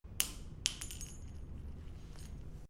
任务枪 foley枪声 " Foley子弹落雪01
描述：福里子弹掉落
标签： 子弹 弗利
声道立体声